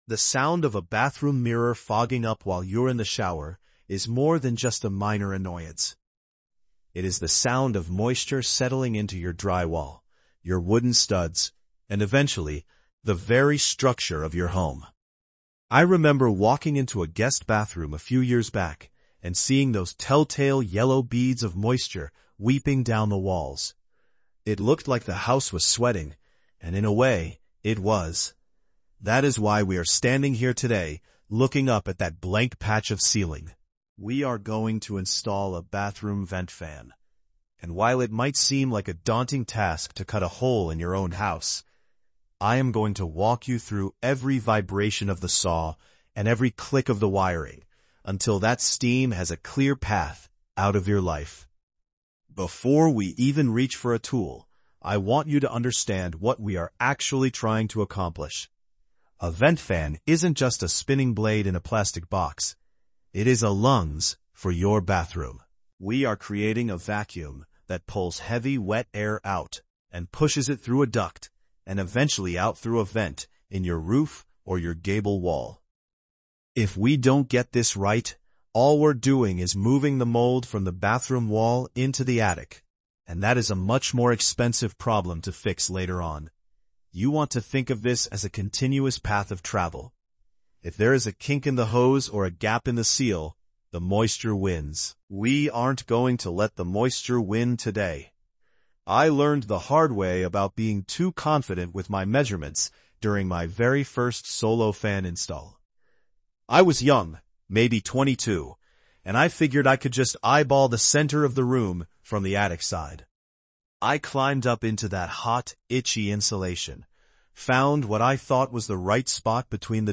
Learn how to install a bathroom vent fan with a calm, step-by-step guide from an experienced mentor.